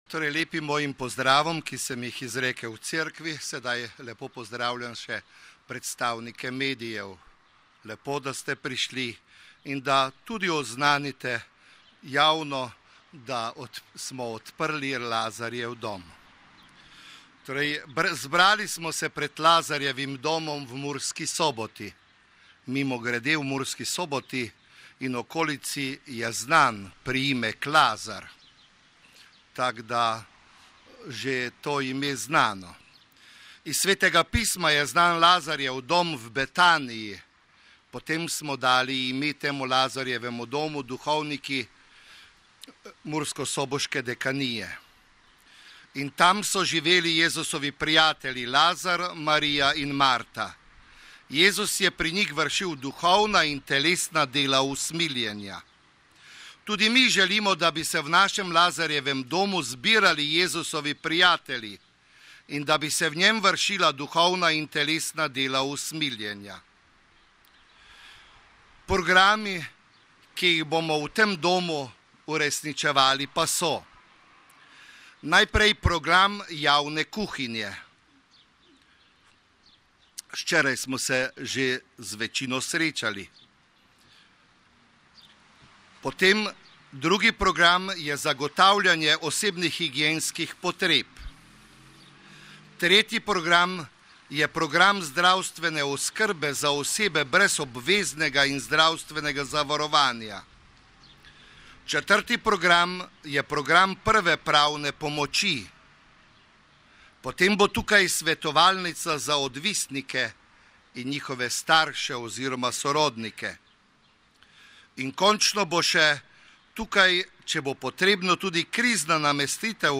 11.00 blagoslovitev Lazarjevega doma
Audio pozdrava